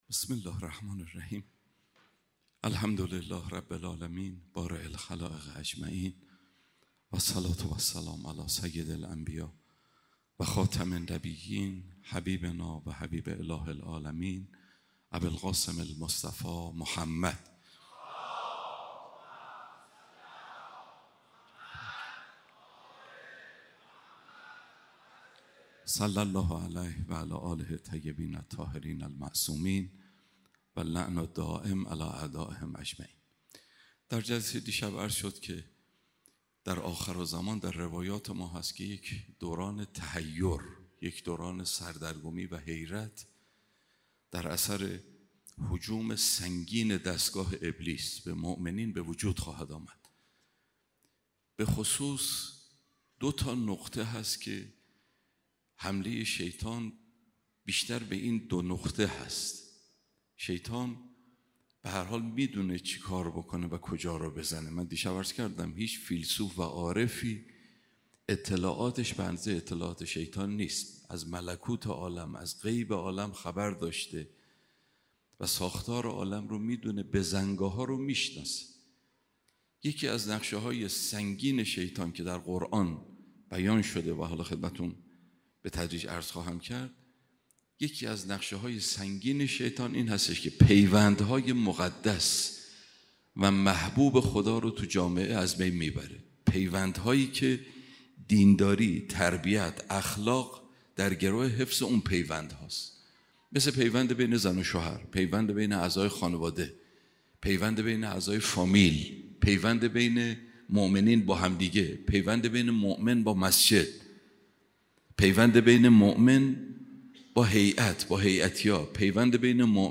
سخنرانی دهه اول محرم 1402